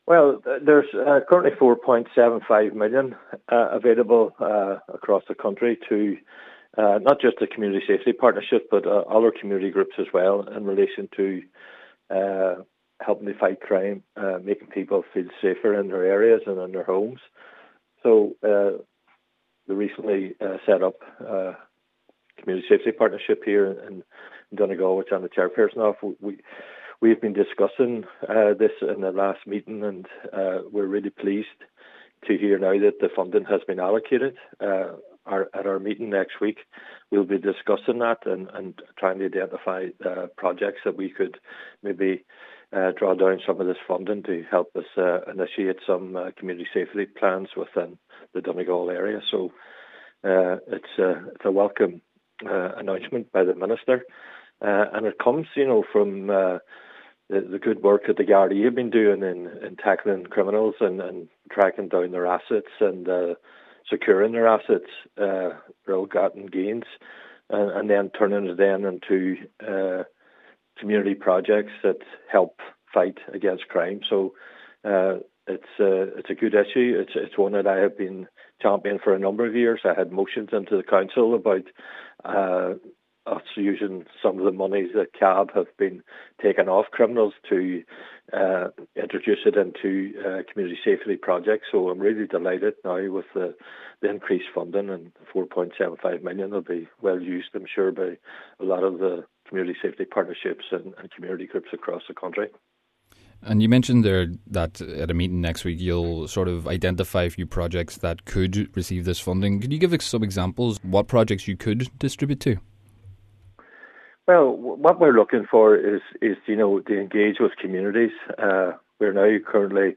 Chairperson of the DLCSP, Cllr Gerry McMonagle, welcomes the funding and explains how it could be used: